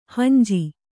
♪ hanji